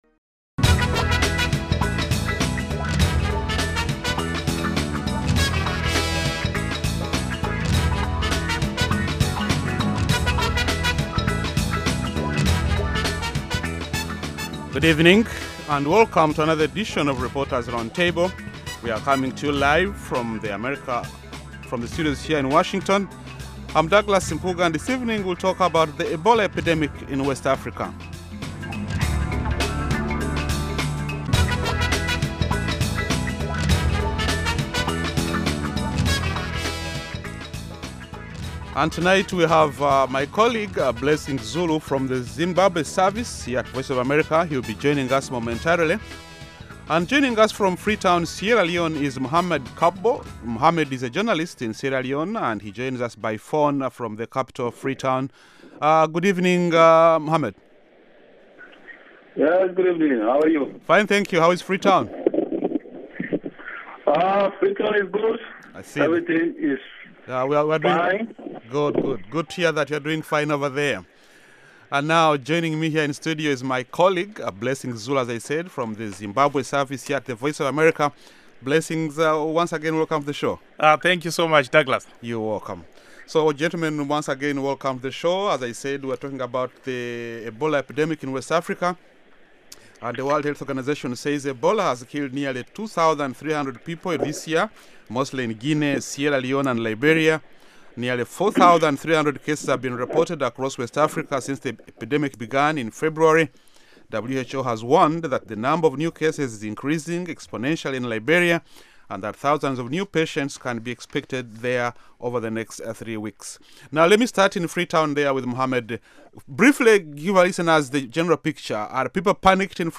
along with a lively panel of journalists